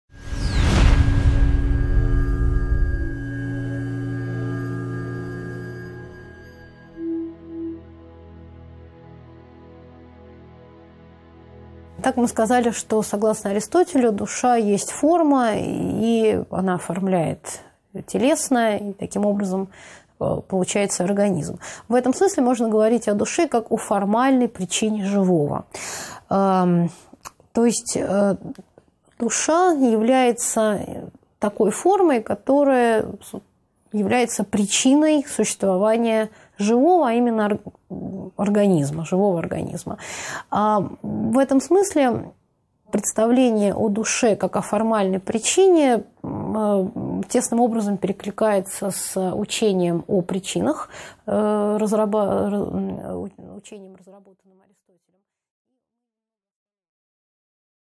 Аудиокнига 3.8 Аристотель о душе и 4-х причинах | Библиотека аудиокниг